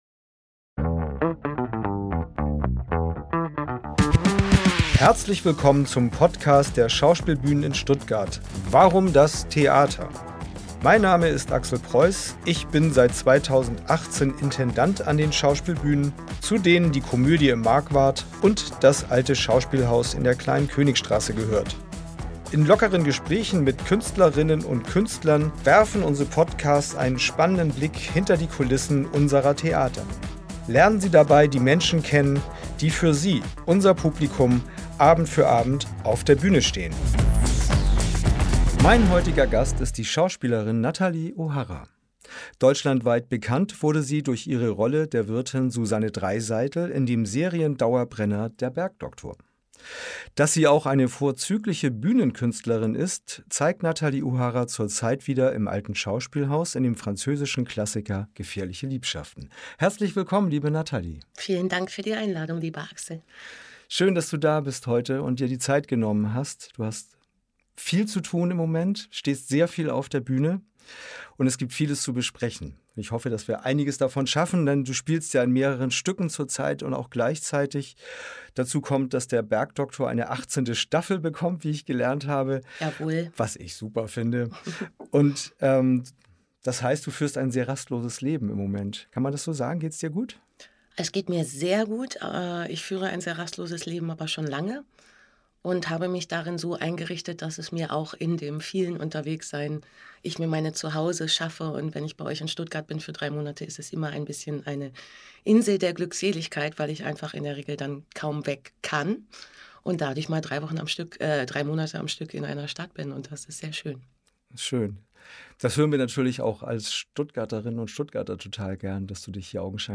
Unser Schauspielbühnen-Talk präsentiert Ihnen regelmäßig Gespräche mit unseren Künstlerinnen und Künstlern. Lernen Sie die Menschen hinter den Bühnencharakteren kennen und erfahren Sie, wie Theater hinter den Kulissen funktioniert.